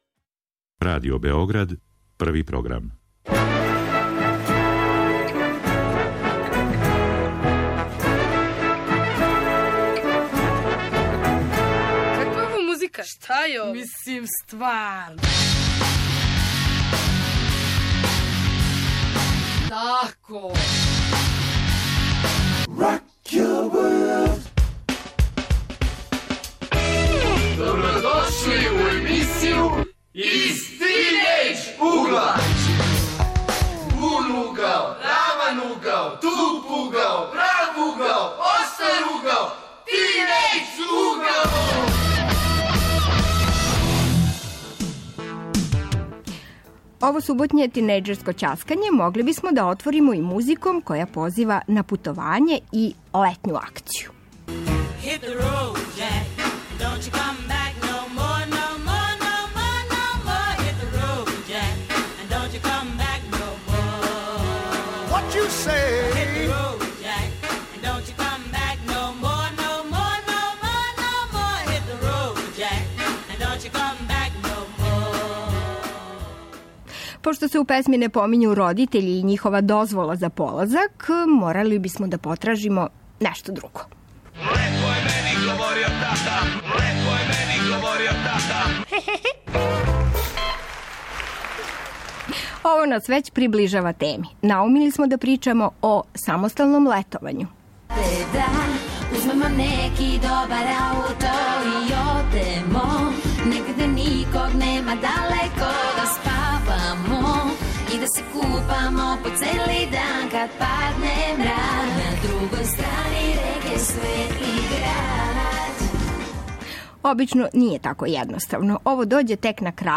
Ове суботе тинејџери ћаскају о самосталном летовању...